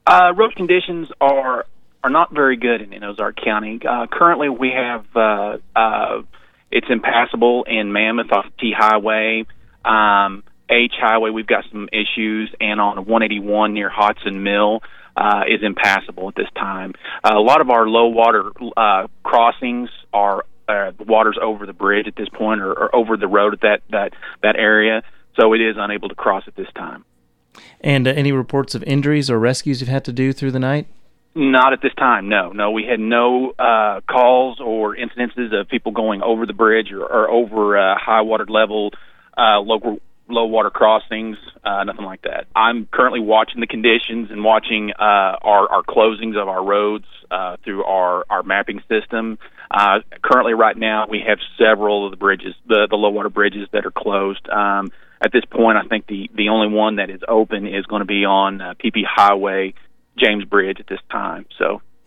Road conditions poor in Ozark County; Saturday noon update from Sheriff